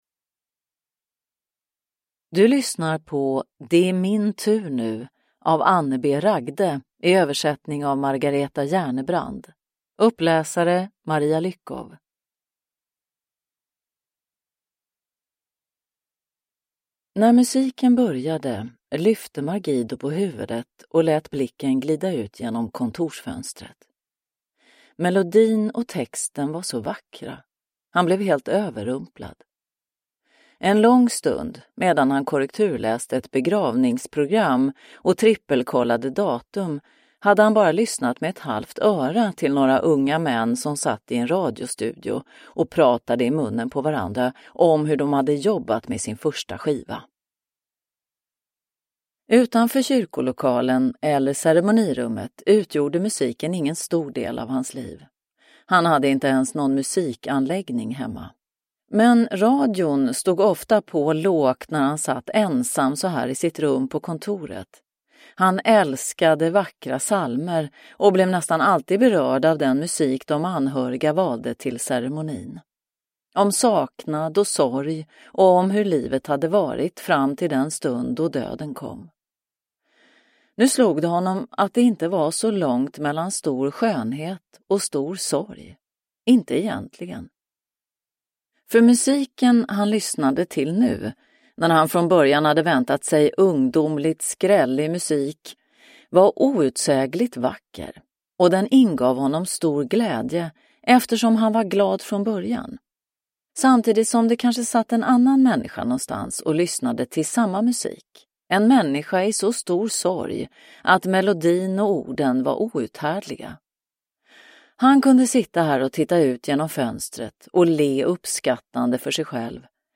Det är min tur nu – Ljudbok – Laddas ner